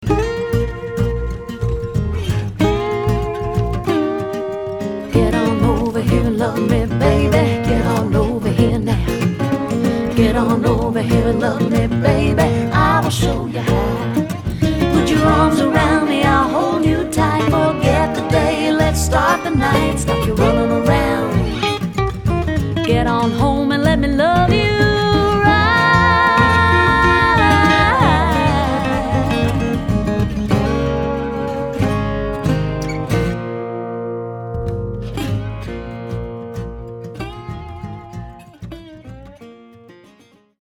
Back up Vocals